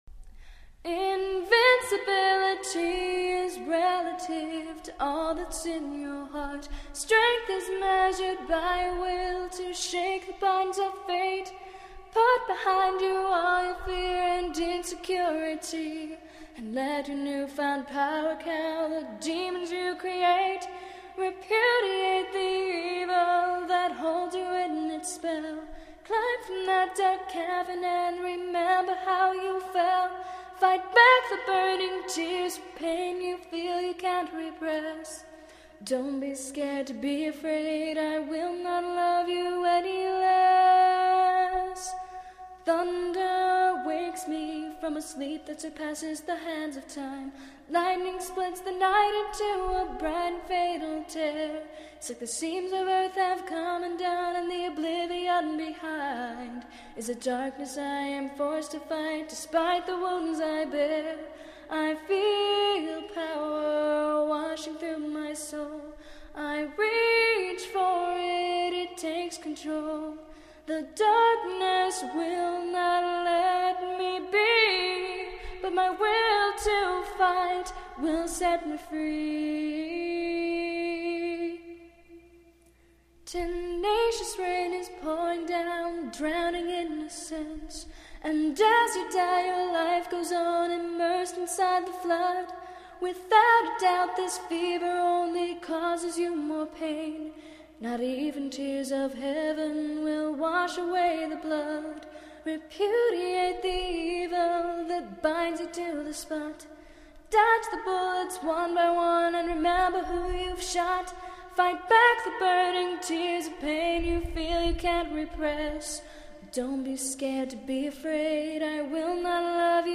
A Capella Version